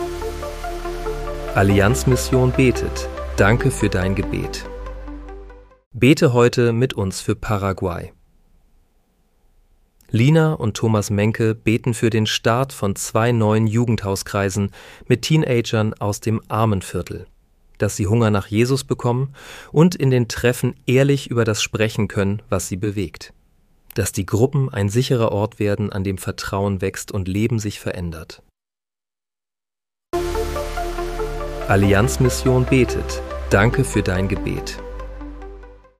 Bete am 16. März 2026 mit uns für Paraguay. (KI-generiert mit der